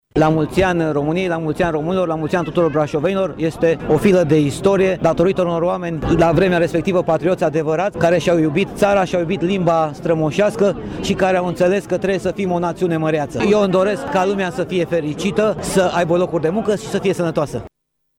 În ciuda vremii friguroase, peste o mie de brașoveni au fost prezenți de-a lungul Bulevardului Eroilor, acolo unde s-a desfășurat parada dedicată Zilei Naționale a României.
Prefectul Brașovului, Ciprian Băncilă: